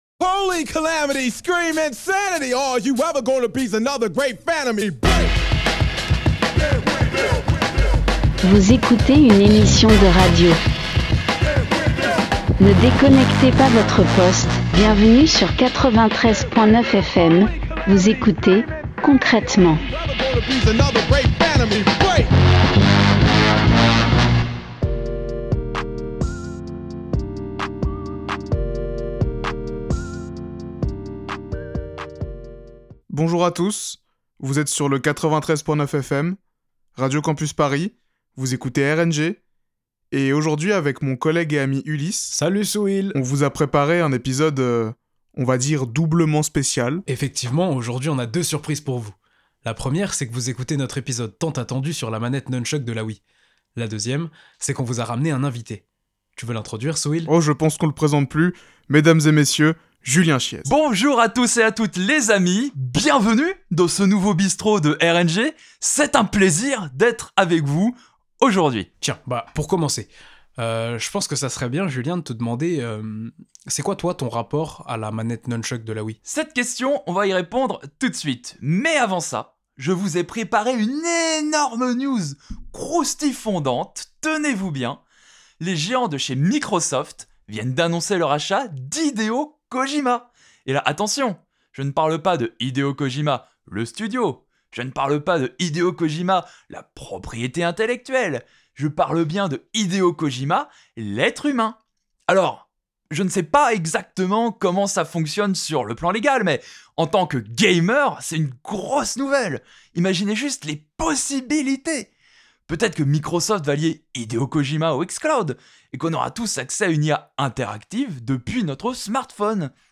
Partager Type Création sonore Société vendredi 1 mars 2024 Lire Pause Télécharger Les gamers sont cringe.